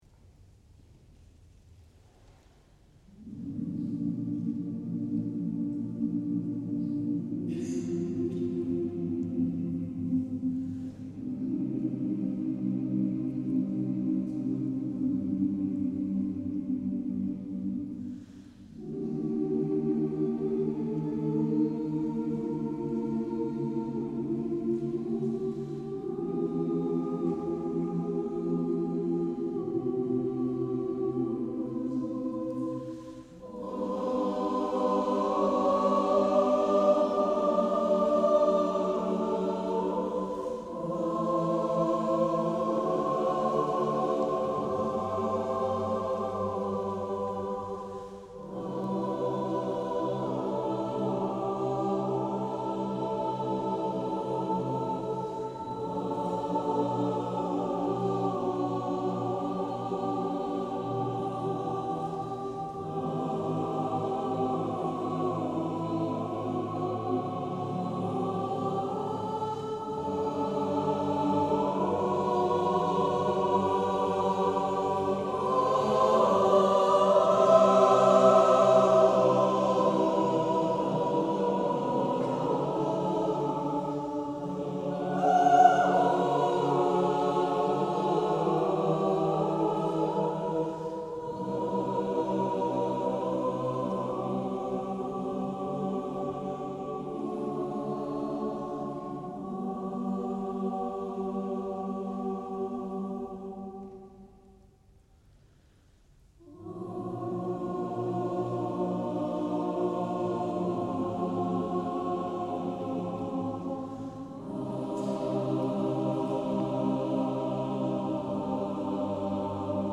Concert du 2 décembre 2018 à Delémont